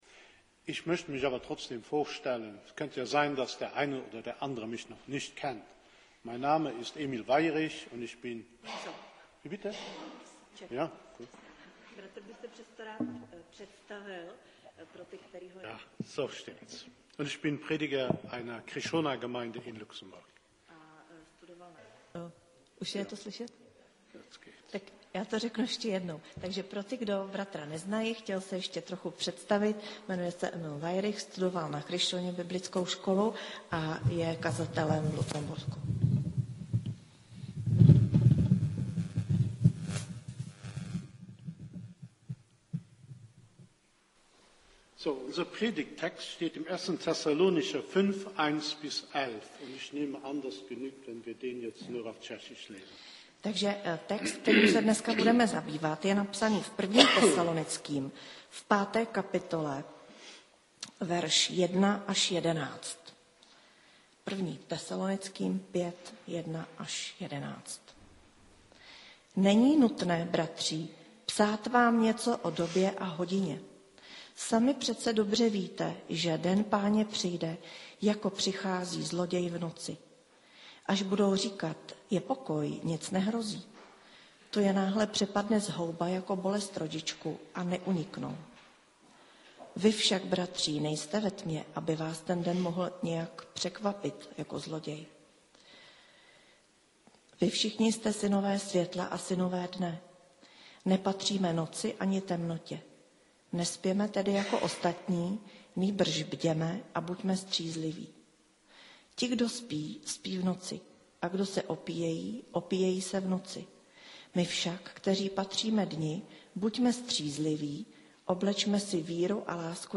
Kategorie: Nedělní bohoslužby Husinec